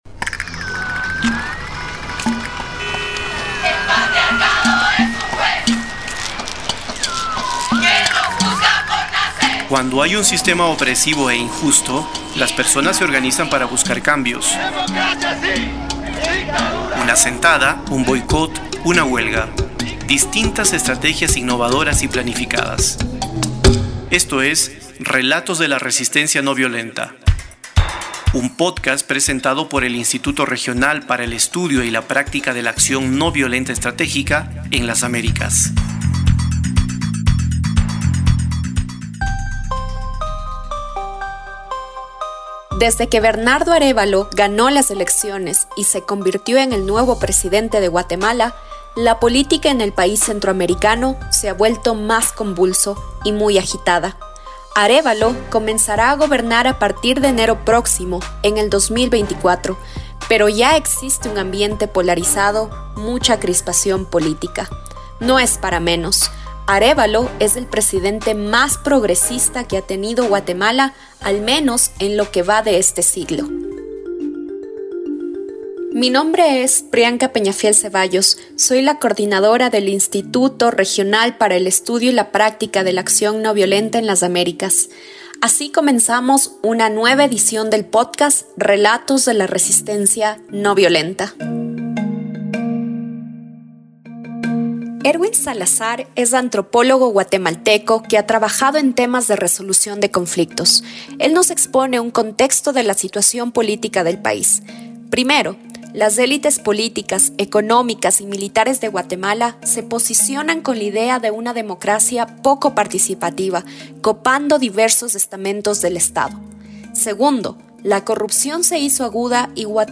Tamaño: 44.48Mb Formato: Basic Audio Descripción: Entrevista - Acción ...